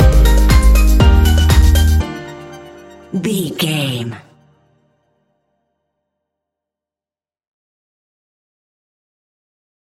Ionian/Major
D♯
house
electro dance
synths
techno
trance
instrumentals